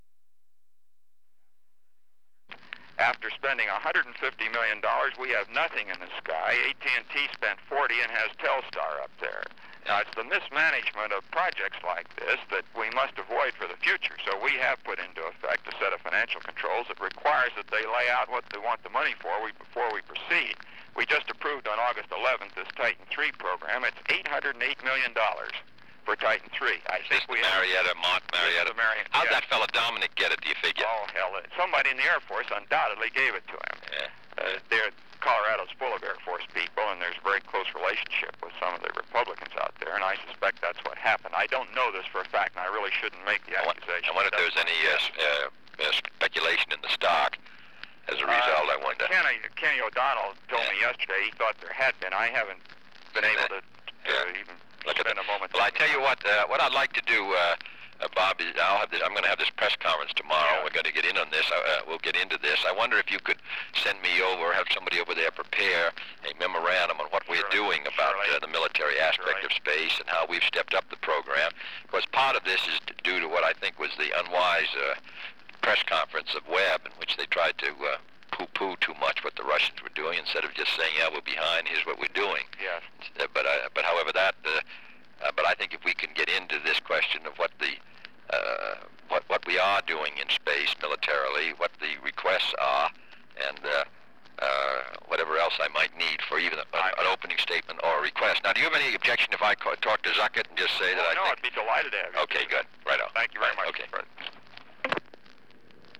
Conversation with Robert McNamara (cont.)
Secret White House Tapes | John F. Kennedy Presidency Conversation with Robert McNamara (cont.)